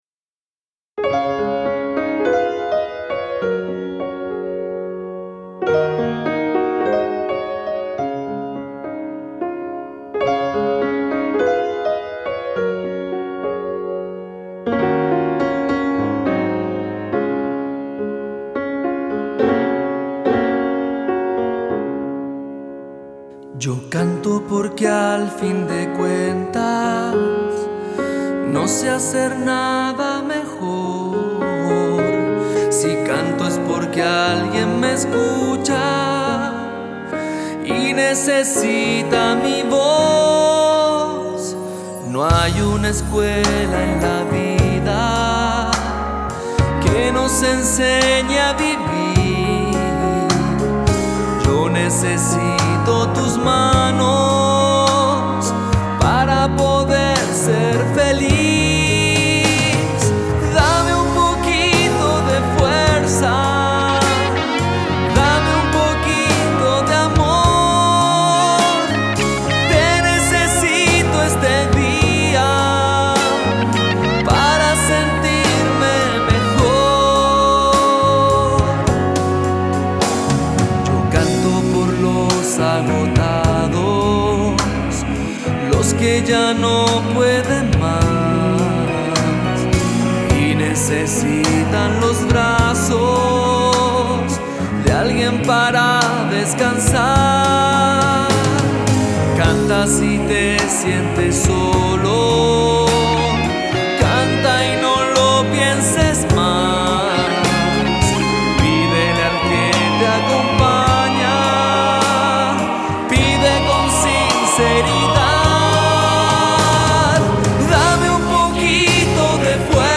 GenerePop / Musica Leggera